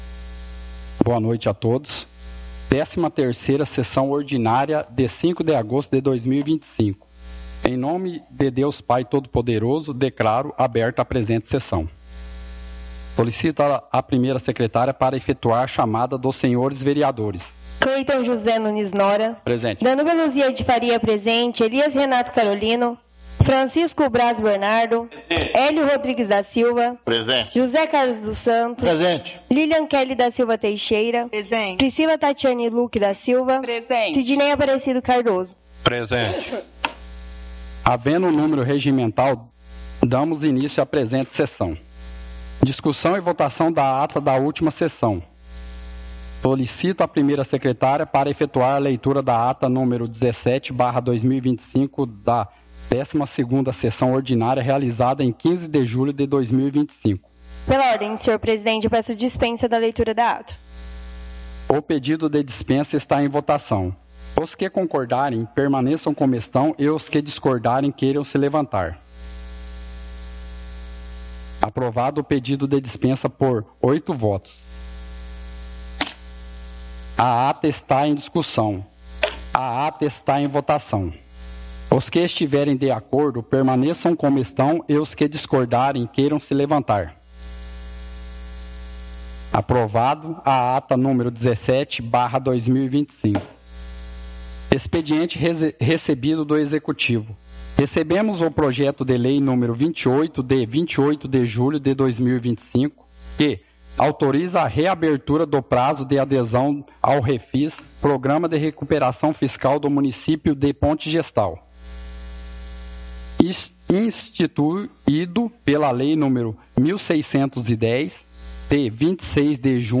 Áudio da 13ª Sessão Ordinária – 05/08/2025